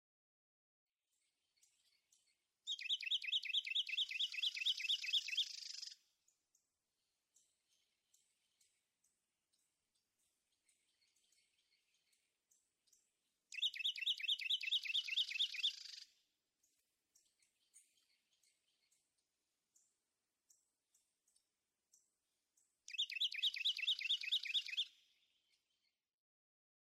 Carolina wren
Subchapter: Female song and duets
In this daytime example, the female offers her duetting chatter three times in response to the male's songs.
Audubon Corkscrew Swamp Sanctuary, Naples, Florida.
049_Carolina_Wren.mp3